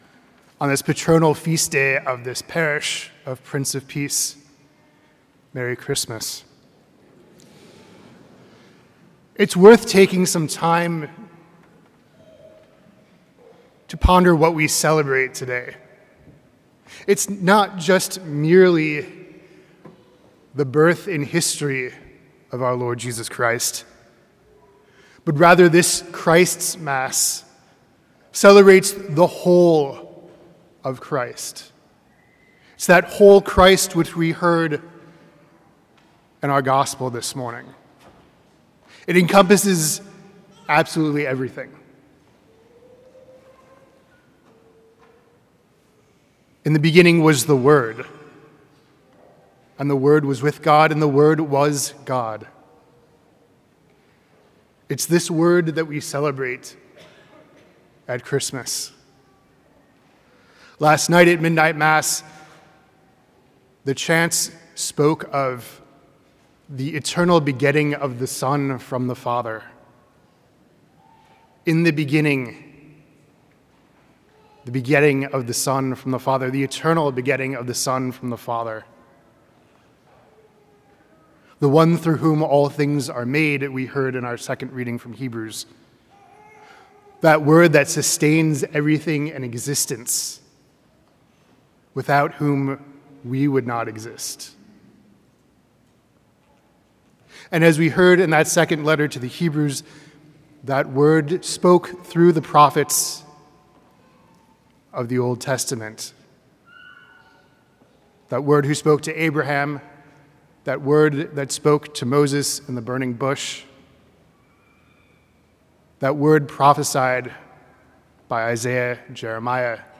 preaches the homily on Christmas Day